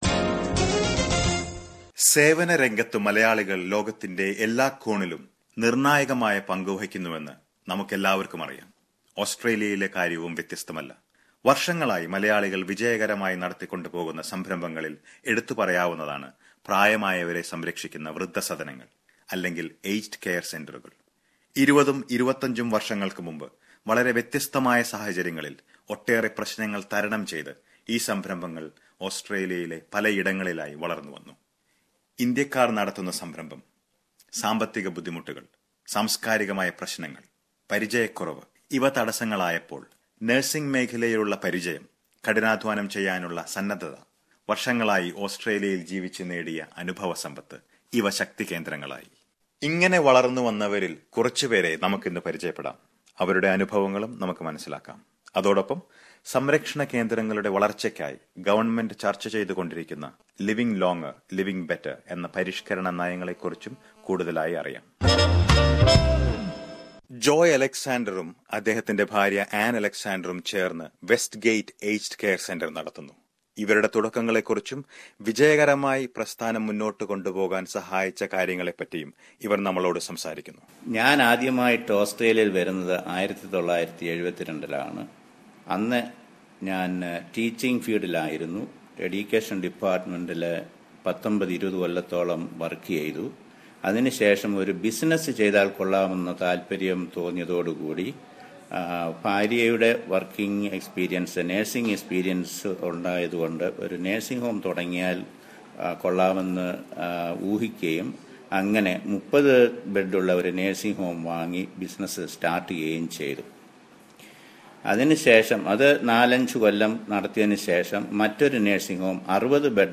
A report on the aged care centres run by Malayalees